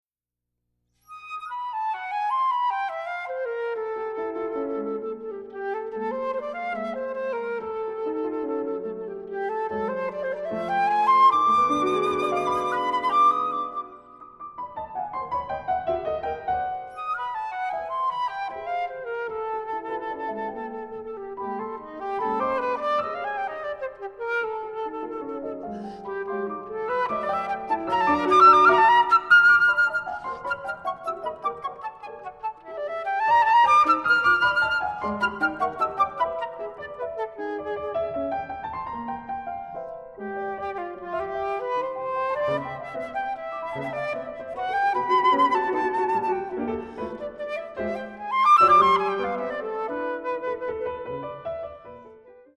flute
piano2:16